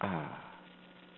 aaah.mp3